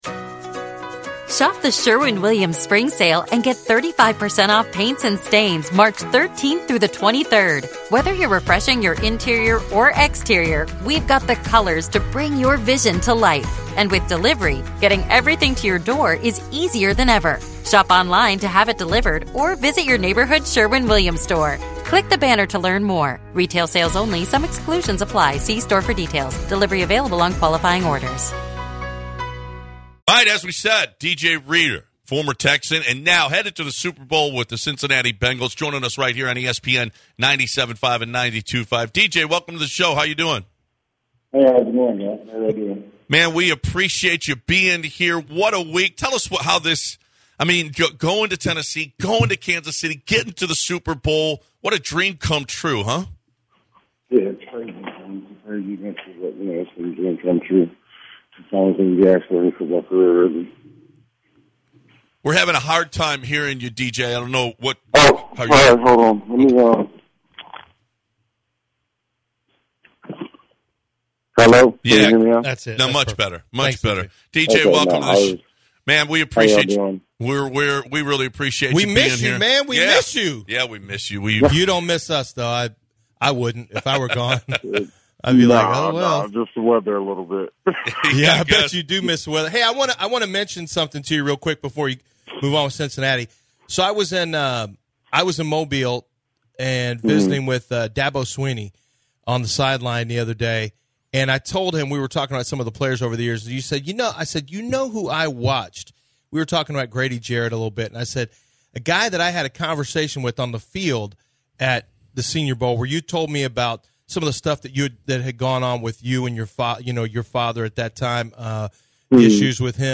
Former Texan DJ Reader calls in to discuss his time in Houston and talks about playing in the Super Bowl.